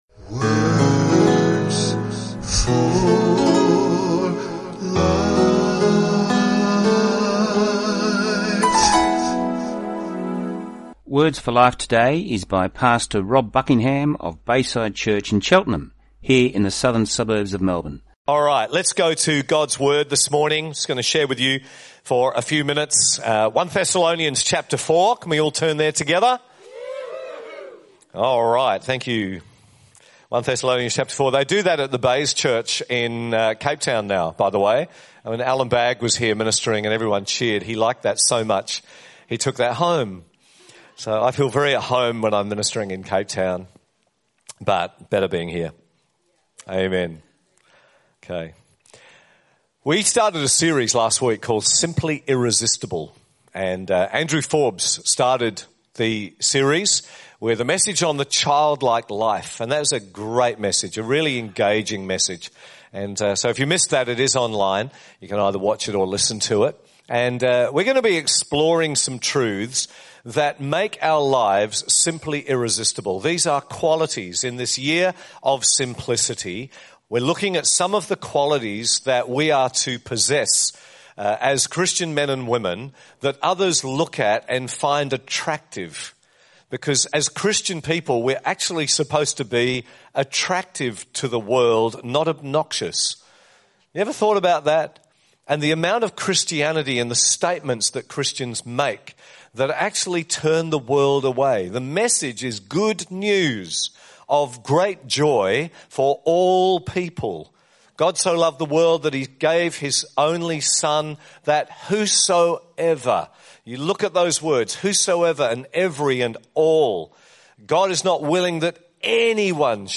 Talk time is 25 minutes.